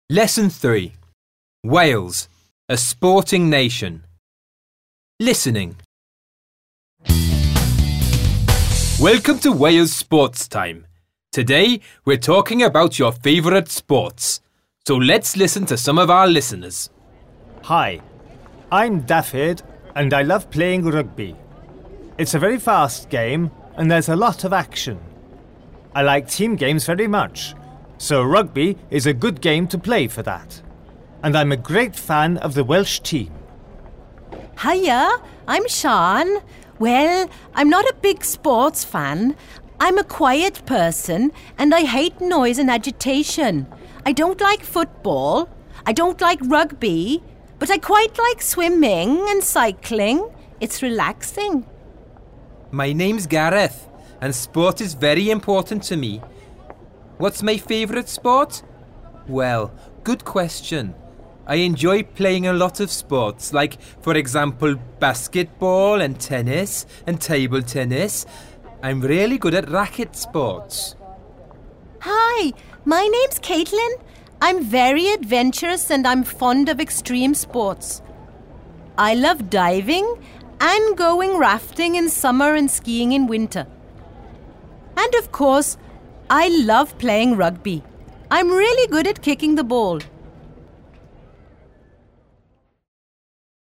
Listen and take notes about each teenager: